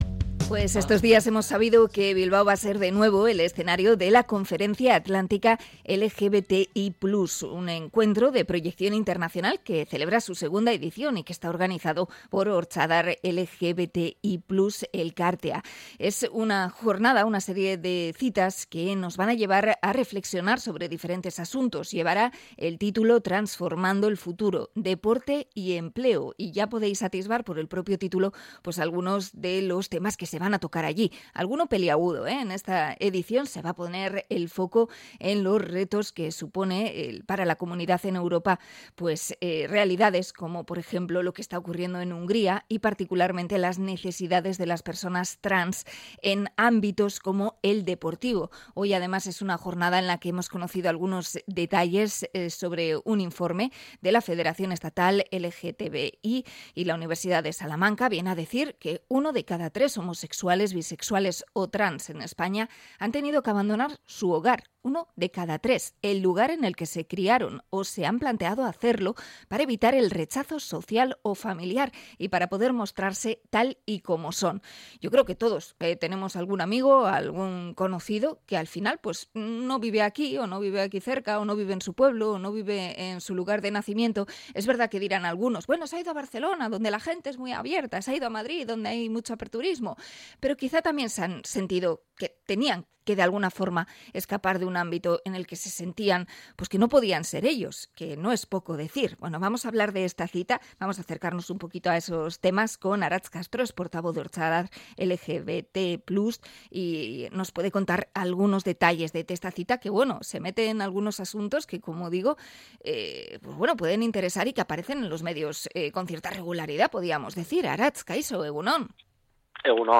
Entrevista a Ortzadar por la Conferencia Atlántica LGBTI+